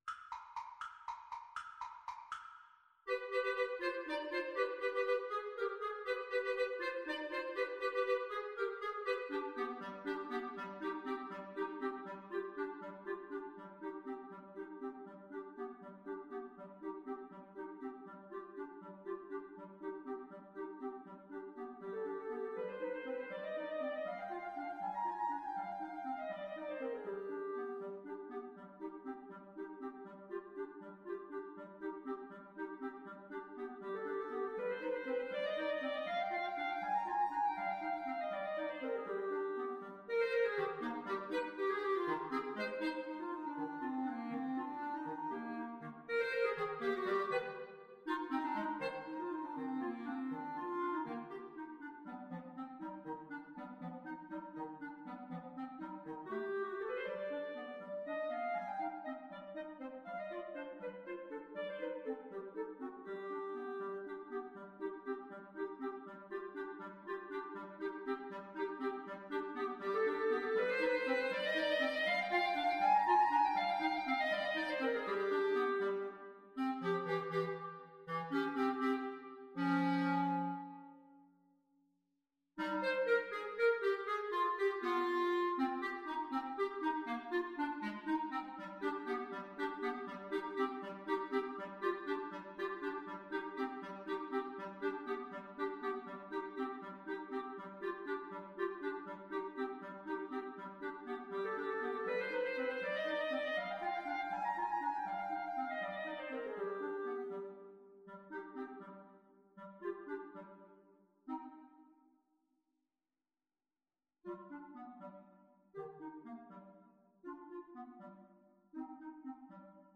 Allegro vivo (.=80) (View more music marked Allegro)
Clarinet Trio  (View more Advanced Clarinet Trio Music)
Classical (View more Classical Clarinet Trio Music)